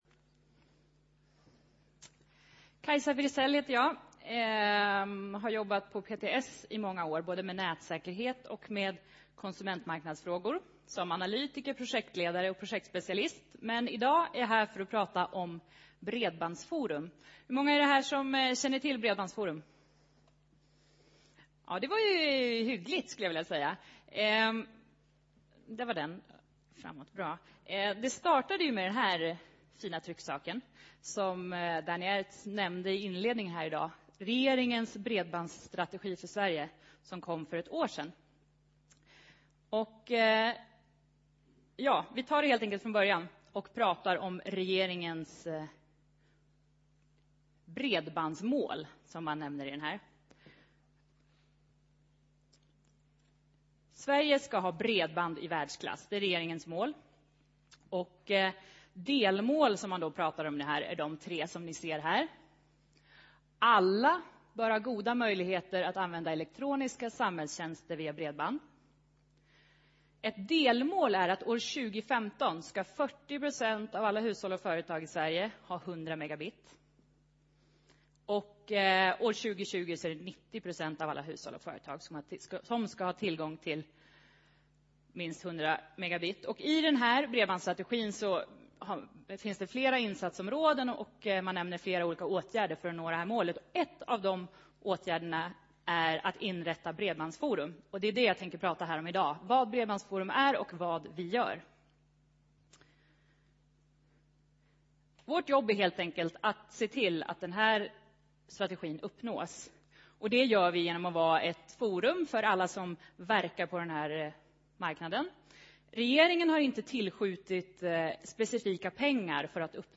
Plats: Kongresshall C
Paneldebattör